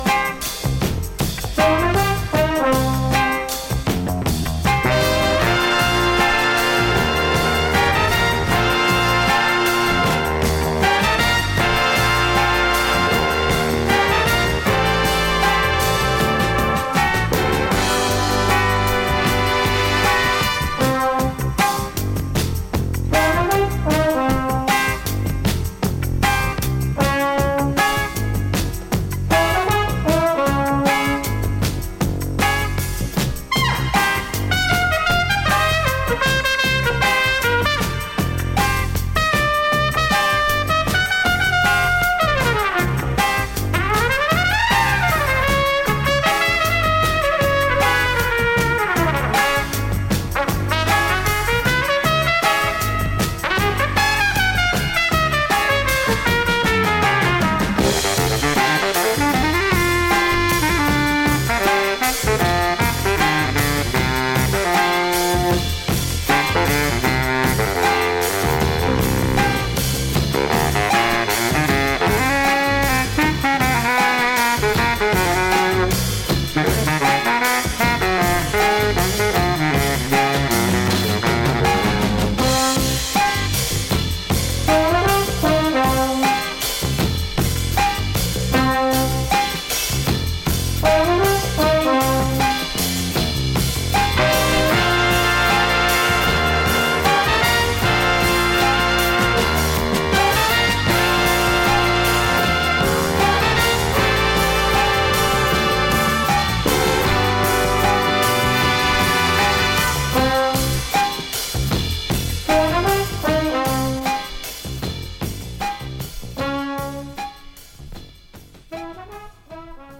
bass
trombone
drums